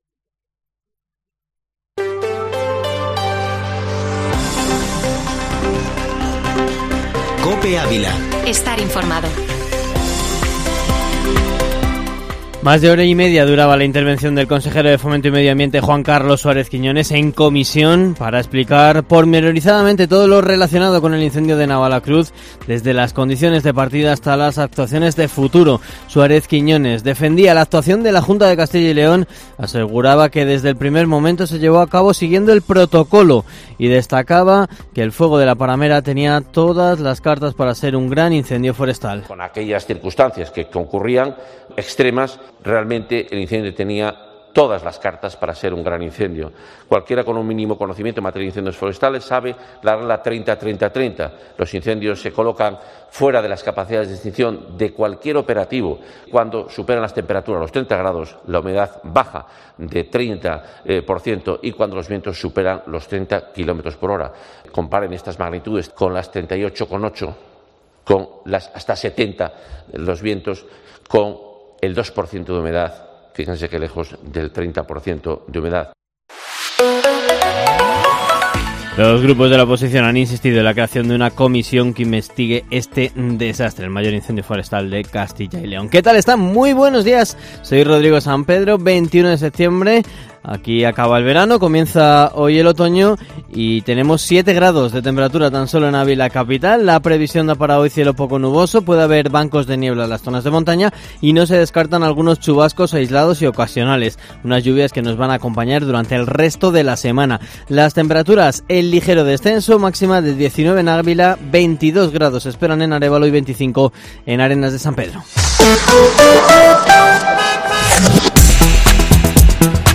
Informativo Matinal Herrera en COPE Ávila -21-sept